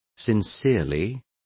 Complete with pronunciation of the translation of sincerely.